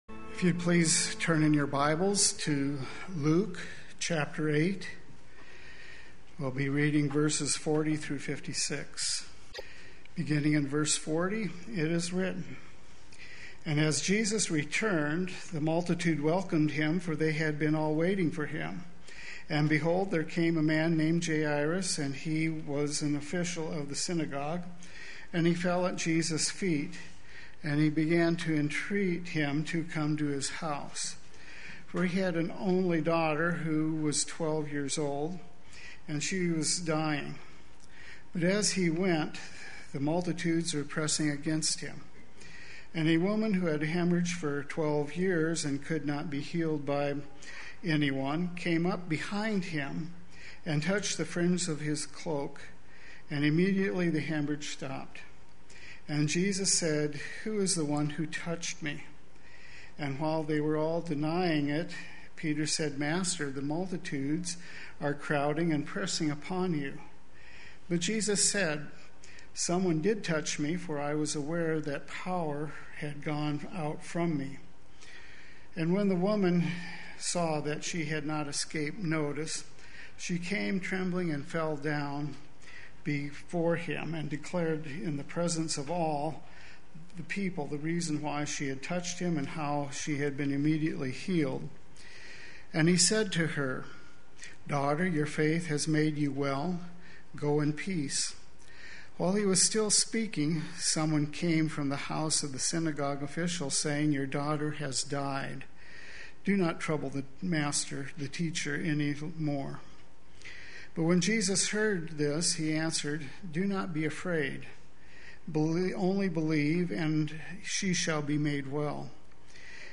Play Sermon Get HCF Teaching Automatically.
Only Believe” Sunday Worship